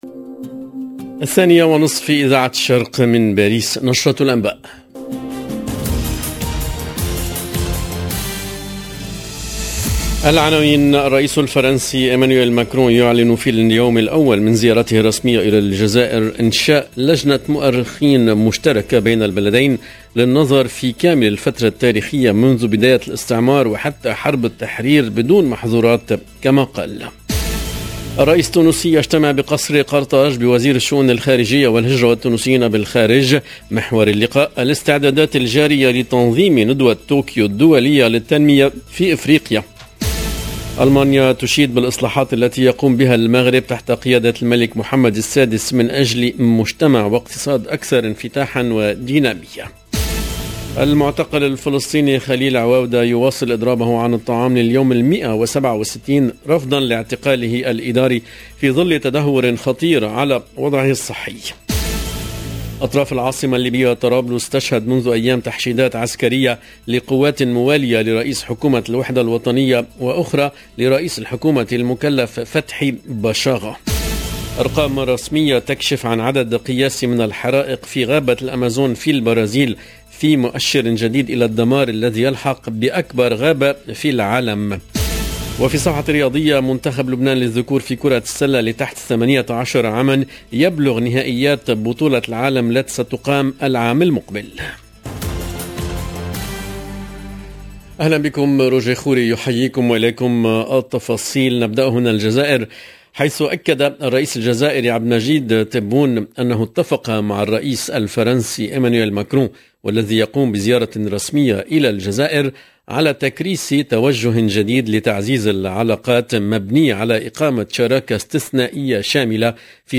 EDITION DU JOURNAL DE 14H30 DU 26/8/2022